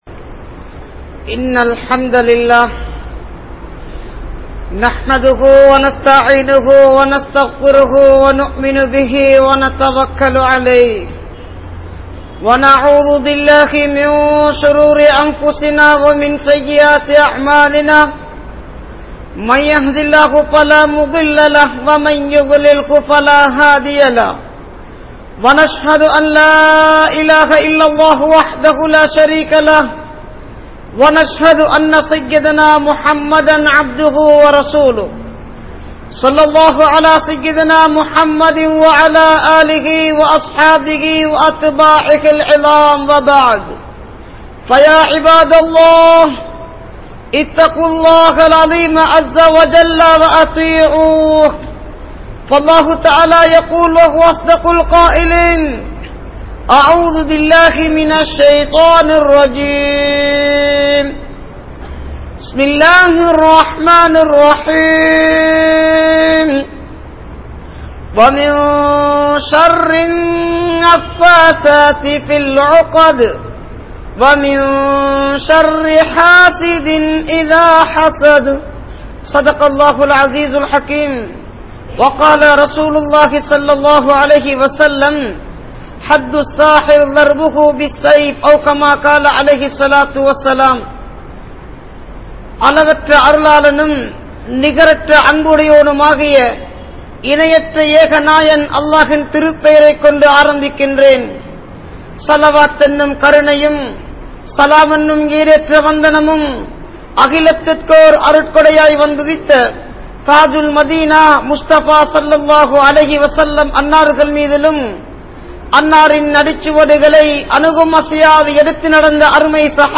Sooniyathin vilaivuhal (சூனியத்தின் விளைவுகள்) | Audio Bayans | All Ceylon Muslim Youth Community | Addalaichenai
Mallawapitiya Jumua Masjidh